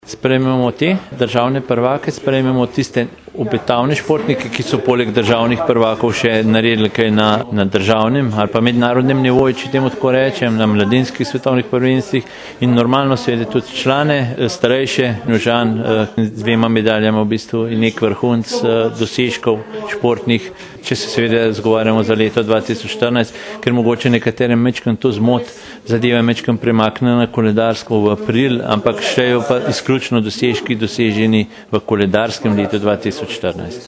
Na prireditvi bodo podelili priznanja najbolj obetavnim in najboljšim športnikom Tržiča za leto 2014 ter tistim, ki so v preteklem letu osvojili naslov državnega prvaka. Več nam je v izjavi povedal